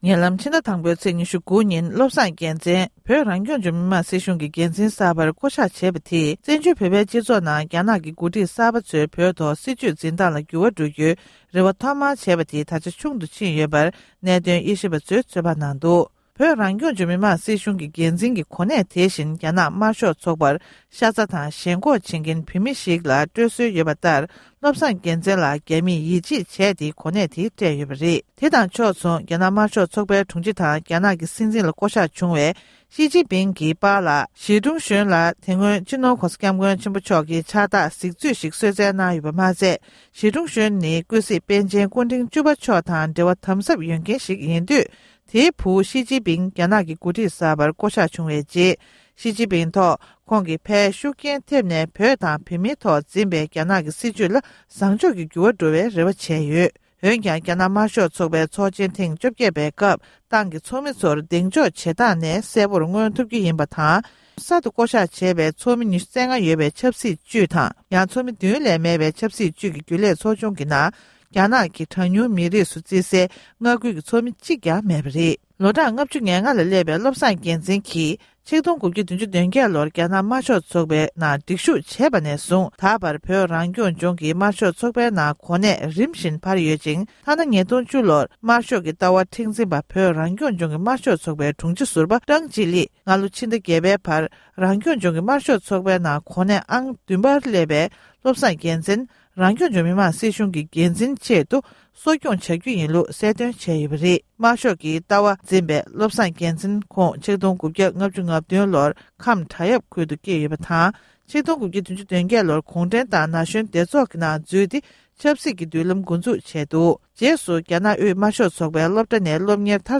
གསར་འགྱུར་དཔྱད་གཏམ།